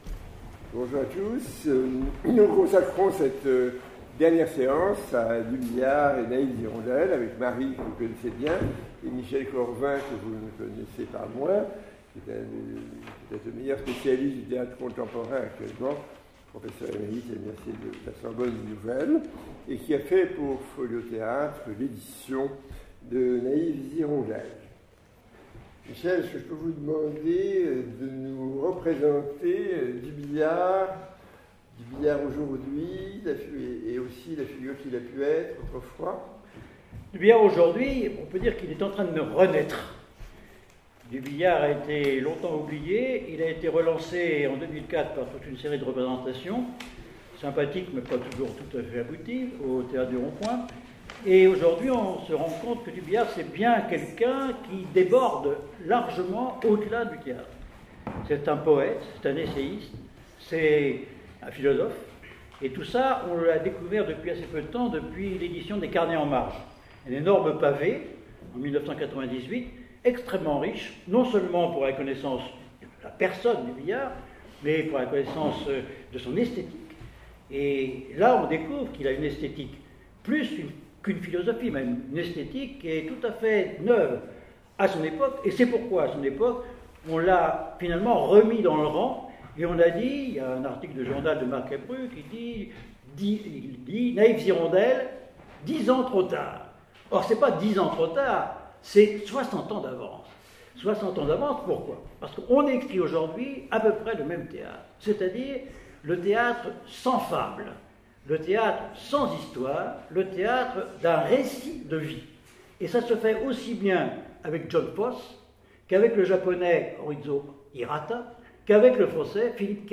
Lire le theatre - Naives Hirondelles
18_lireletheatre_naives-hirondelles.mp3